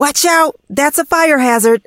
maisie_hurt_vo_04.ogg